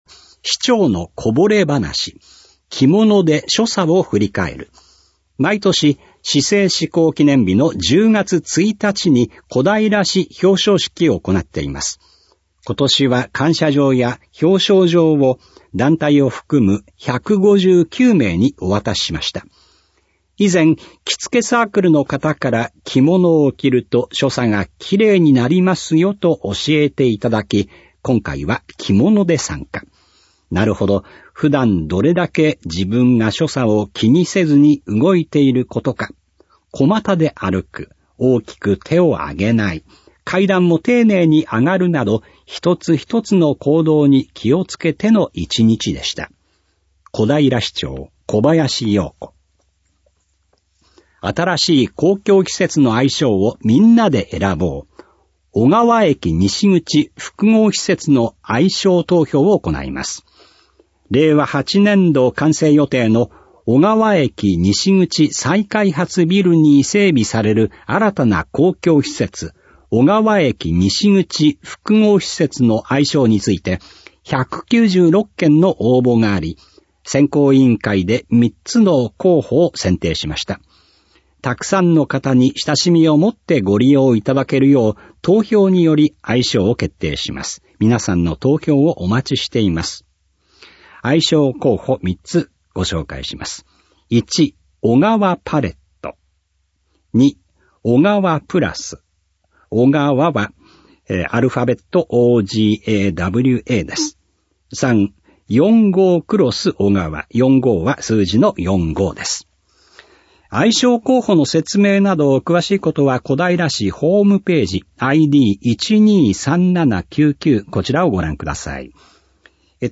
トップ > 市報音声版「声のたより」 > 2025年 > 市報こだいら2025年10月20日号音声版